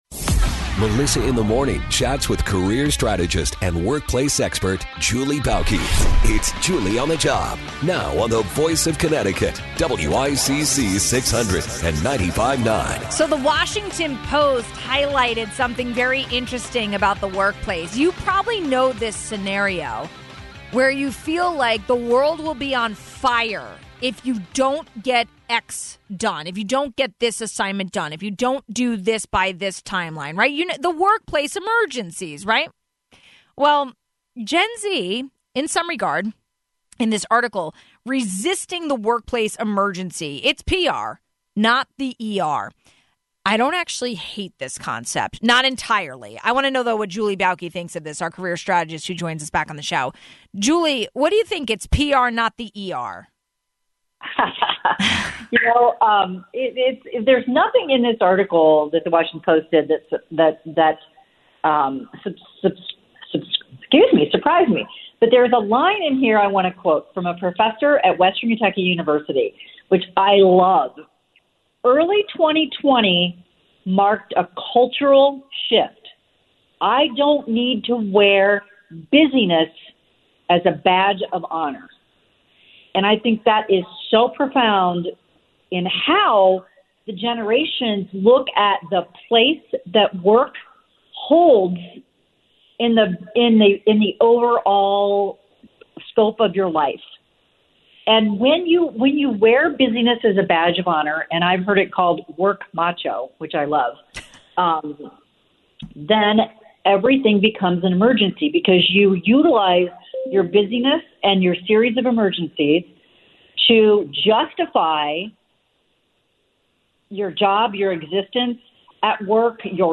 Headliner Embed Embed code See more options Share Facebook X Subscribe Maybe the workplace isn’t on fire all the time, but it is sometimes… Not according to Gen-Z workers, according to a recent Washington Post article. We talked about it with career strategist